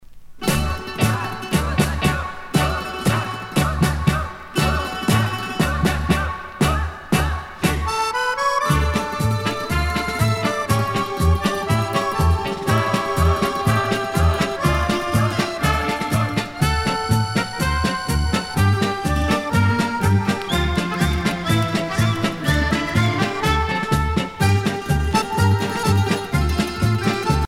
danse : kazatchok